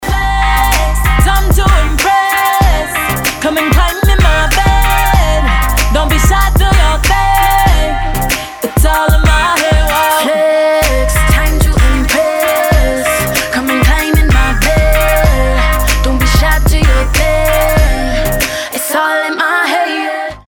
женский вокал
dance
RnB
качает
битовые